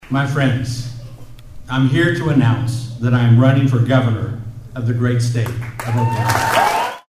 Oklahoma Attorney General Gentner Drummond made a big announcement at the Osage County Fairgrounds on Monday.
Drummond announcement 1-13.mp3